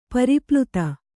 ♪ pari pluta